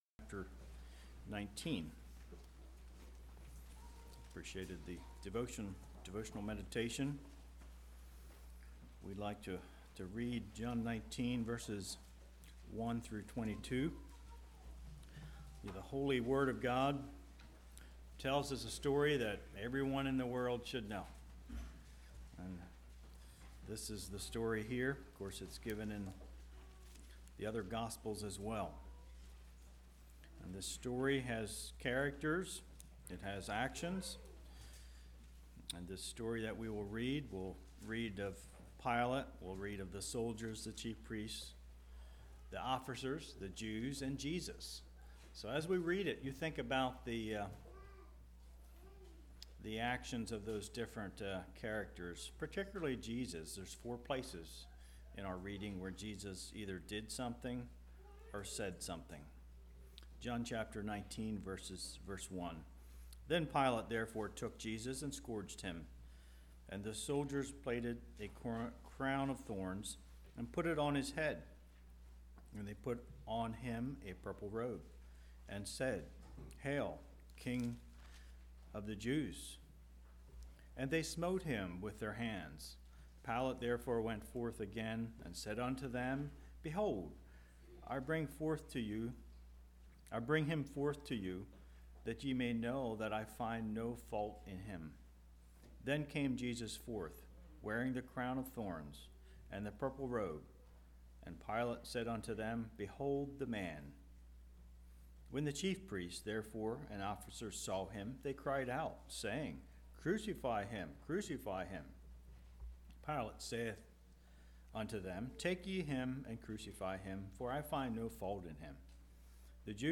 Communion message. A few thoughts taken from three songs.